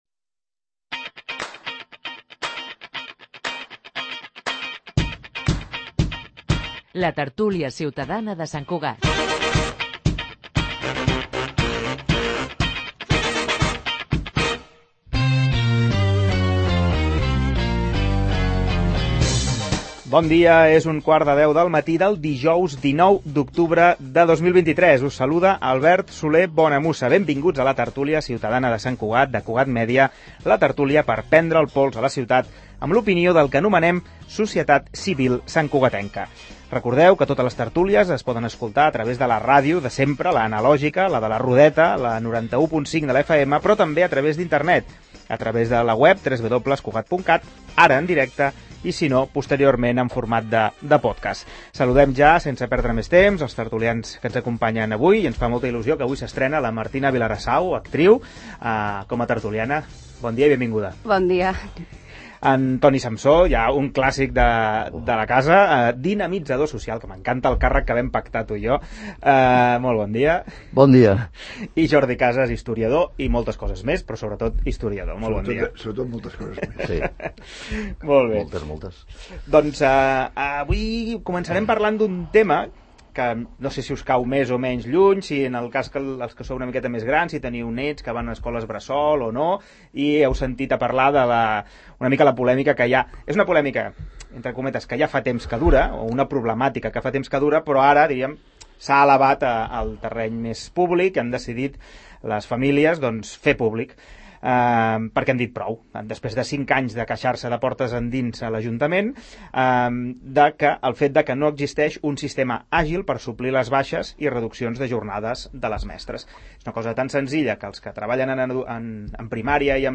En la ‘Tertúlia ciutadana de Sant Cugat’ de Cugat Mèdia d’aquest dijous s’ha parlat de molts temes, començant per la situació de falta de mestres a les escoles bressol municipals. També s’ha debatut sobre les ordenances fiscals congelades i les modificacions en les bonificacions de l’IBI, en especial a les famílies nombroses; de si és més prioritari el quart CAP o l’hospital comarcal de referència; i sobre l’estat de les voreres en alguns punts de la ciutat.